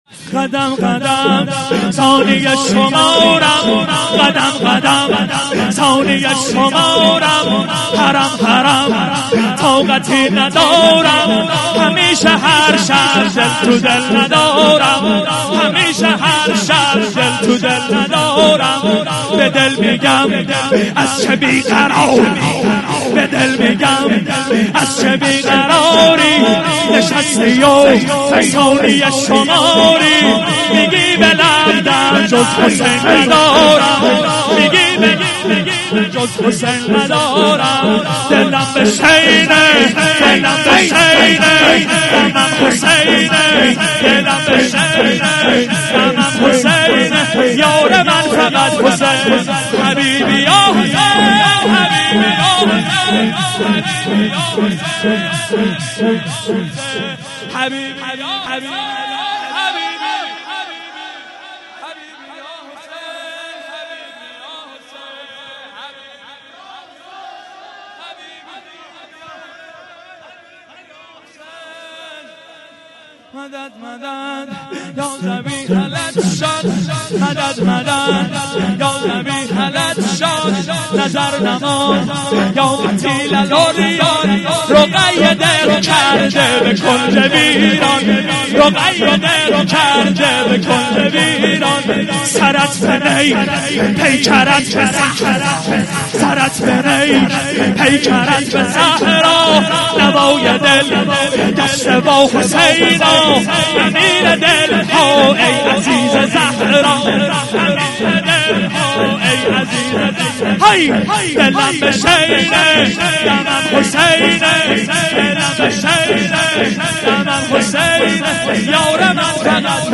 شور - قدم قدم ثانیه شمارم 05.mp3